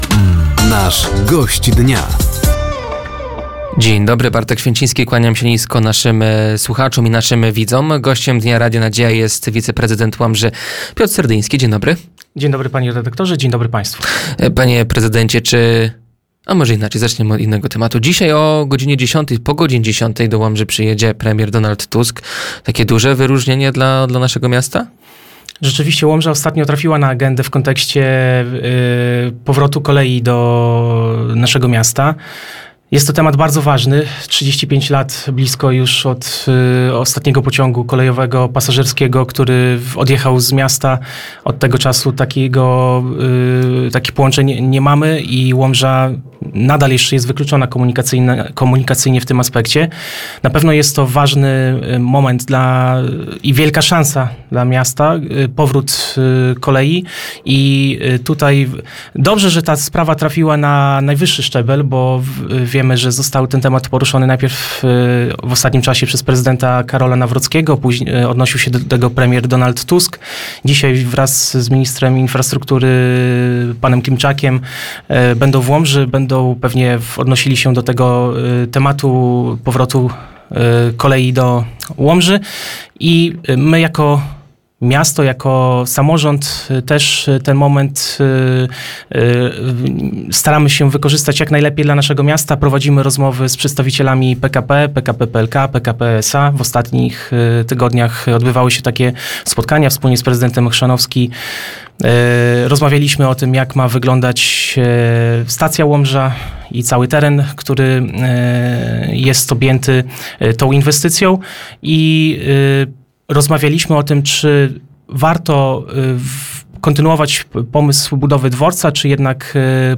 Gościem Dnia Radia Nadzieja był wiceprezydent Łomży Piotr Serdyński. Tematem rozmowy była budowa tężni w Parku Jana Pawła II, Strefa Płatnego Parkowania i parking Park&Ride.